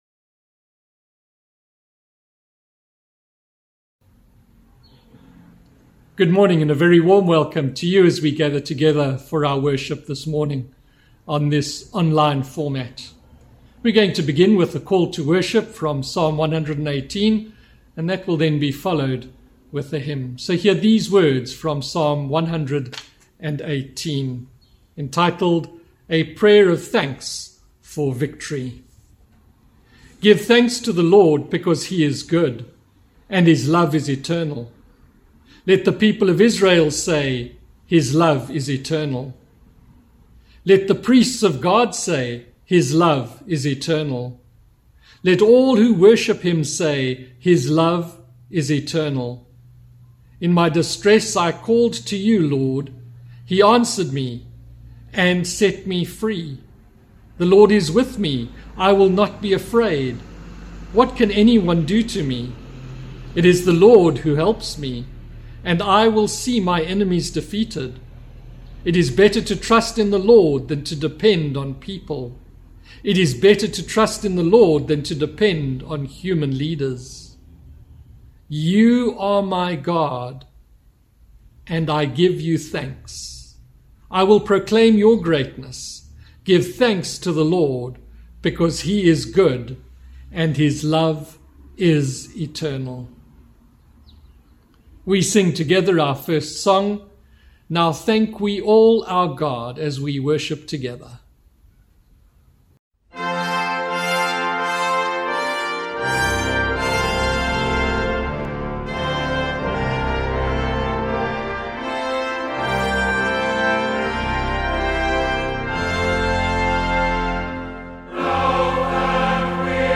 Sermon – 12th November – Thanksgiving